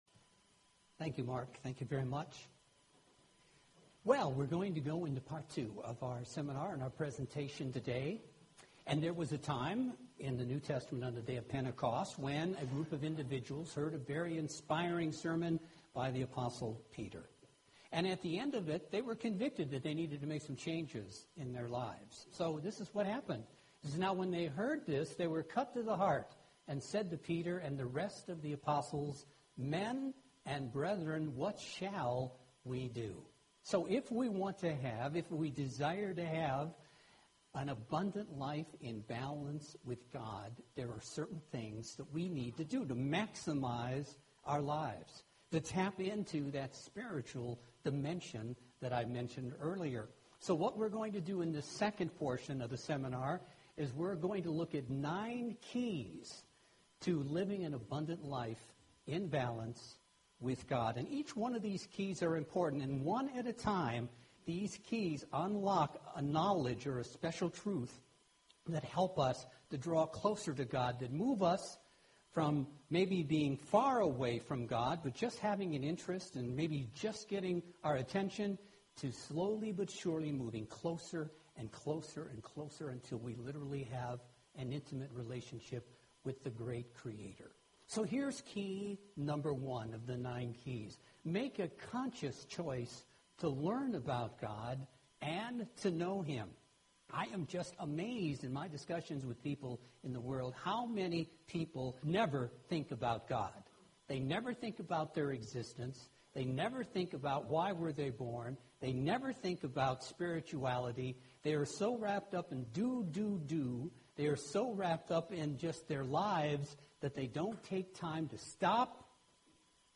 So, is the world and your life here by an intelligent plan or design, or is it here by blind chance or an accident? Let's examine this topic in depth through this Kingdom of God seminar and see how we can live our lives to the fullest.